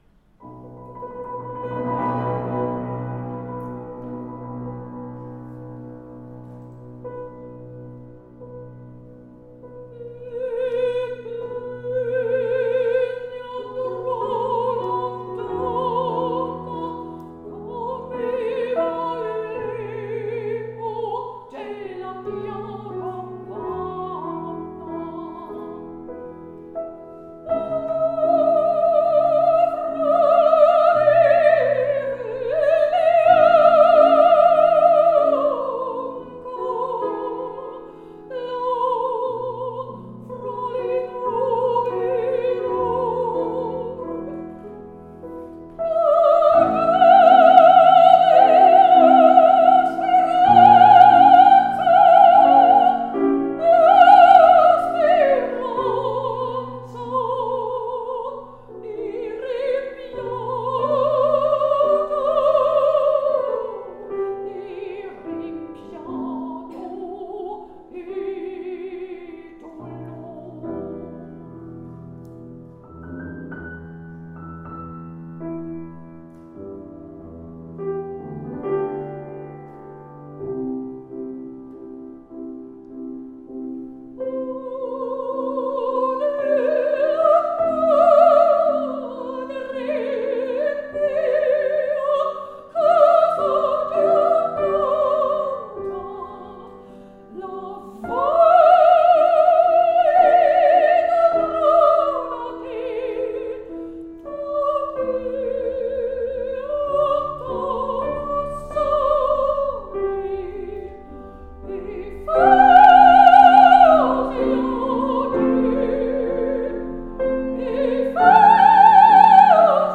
Oper
Konzert am 25.06.2023 im Gemeindesaal der 12-Apostel-Kirche in Schöneberg. Am Klavier